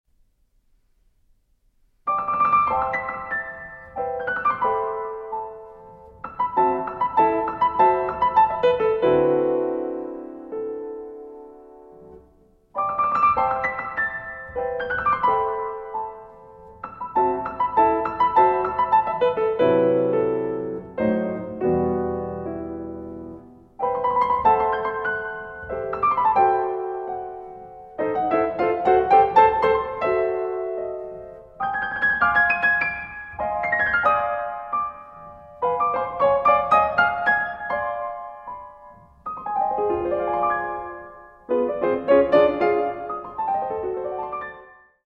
Amabile con moto